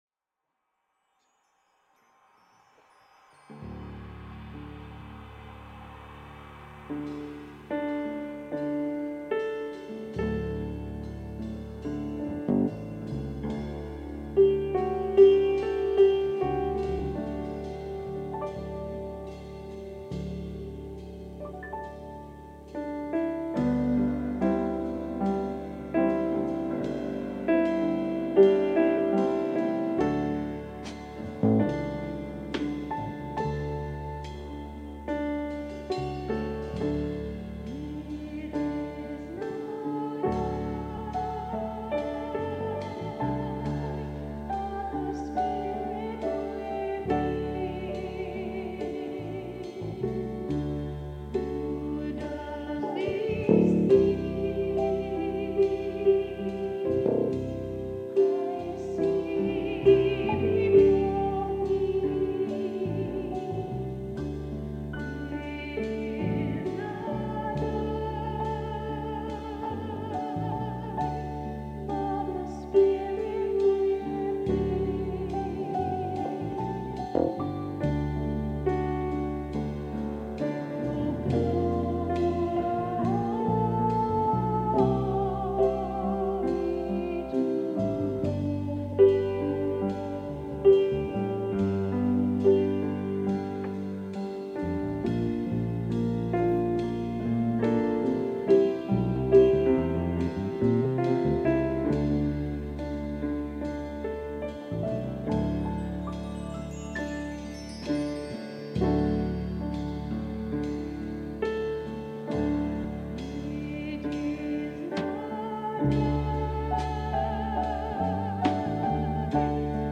The audio recording below the video clip is an abbreviated version of the service. It includes the Meditation, Message, and Featured Song, and will be posted after editing.